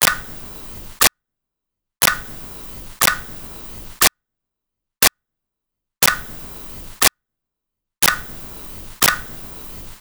Clock Ticking 2
clock-ticking-2.wav